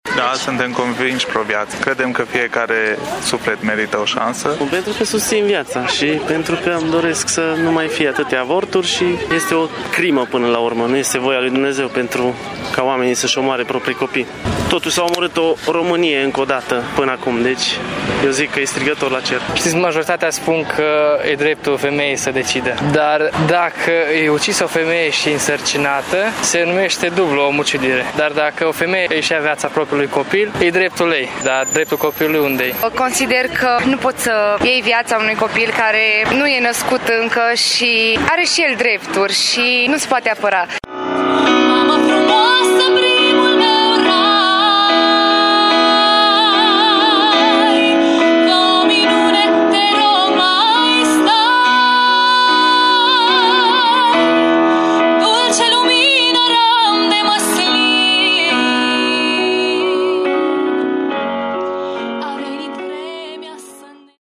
Această ediție a Marșului s-a bucurat, de altfel, de o participare bogată a tinerilor și copiilor: